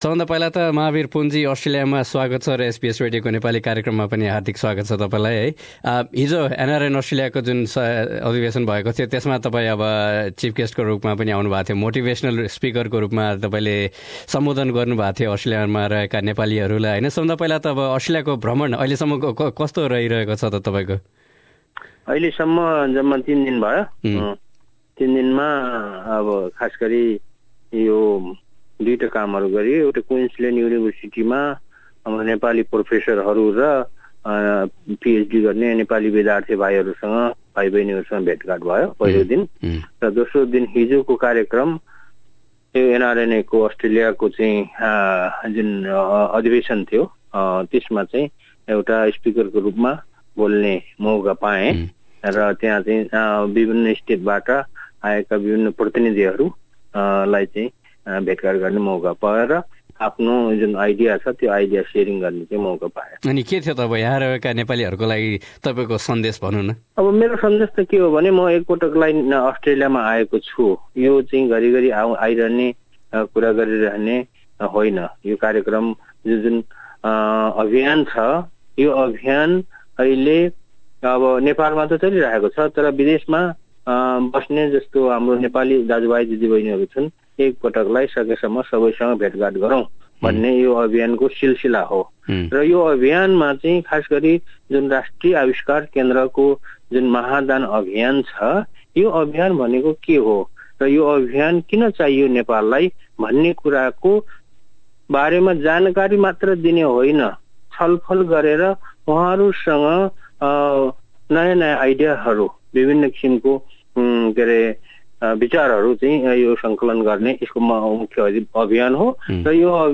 Two decades later, Pun is in Australia to develop ideas with the members of the Nepali community for his project to build a national innovation centre. Mahabir Pun spoke to SBS Nepali.